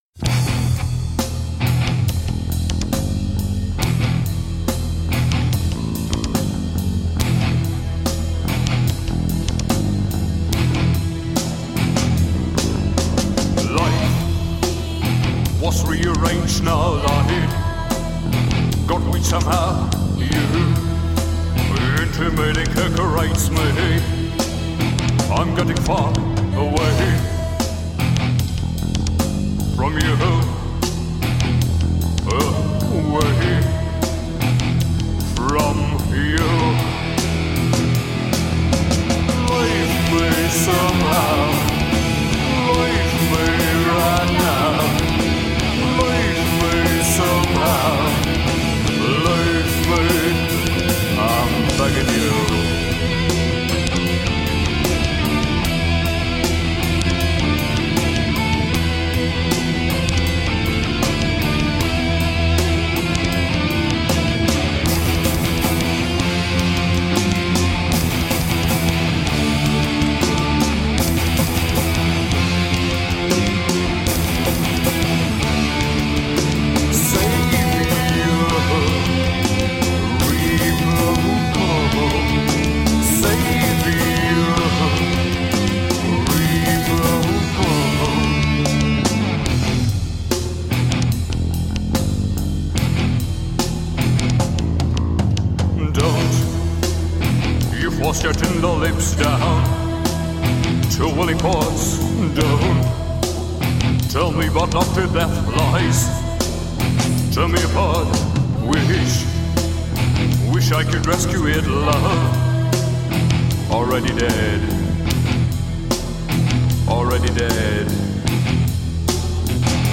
Gothic darkwave hard-edged rock.
Tagged as: Hard Rock, Metal, Darkwave, Goth